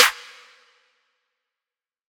TGOD Snare 2.wav